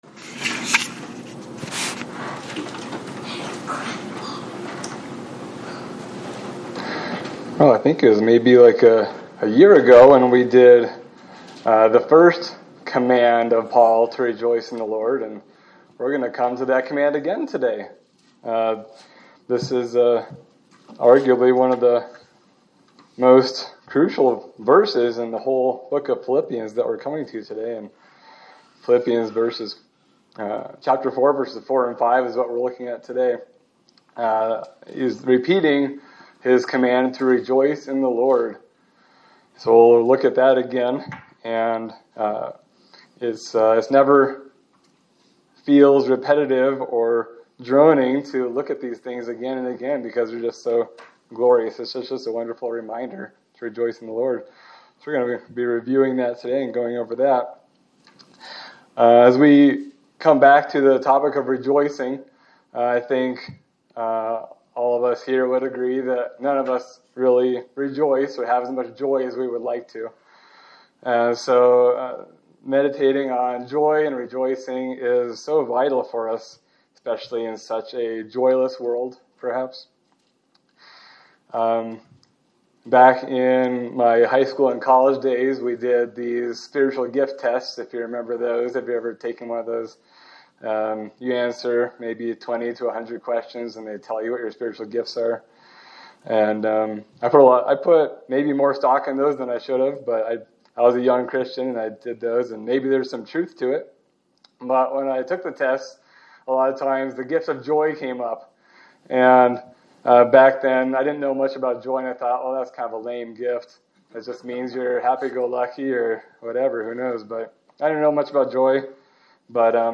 Sermon for January 18, 2026